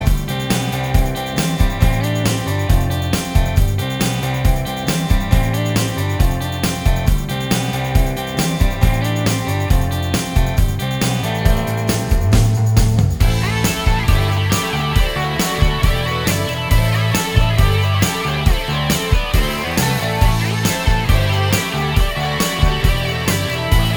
Minus Rhythm Guitar Pop (1980s) 3:19 Buy £1.50